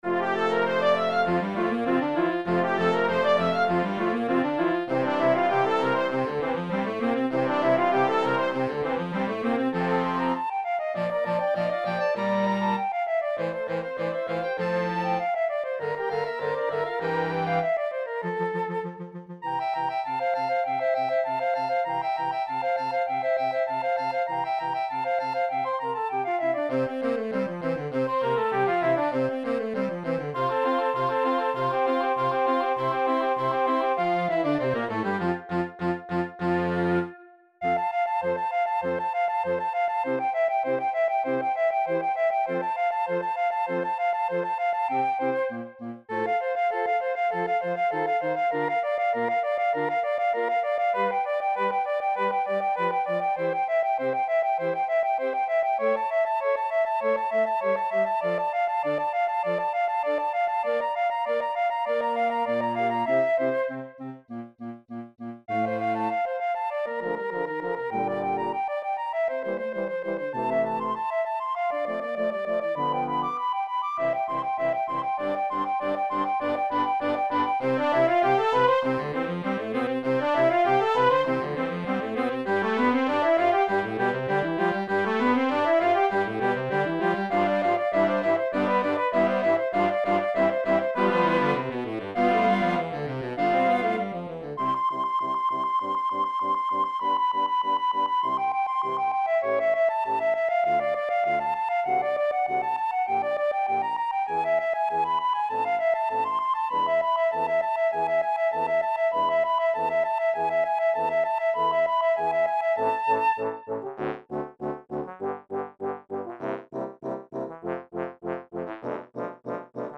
Voicing: Flute and Concert Band